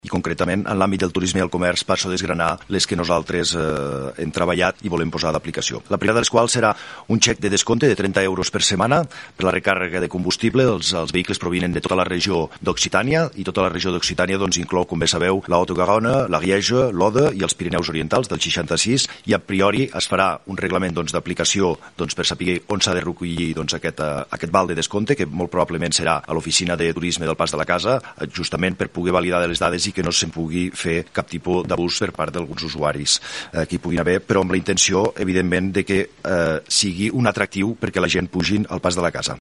El ministre de Turisme, Jordi Torres, ha donat més detalls sobre aquest xec.